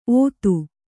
♪ ōtu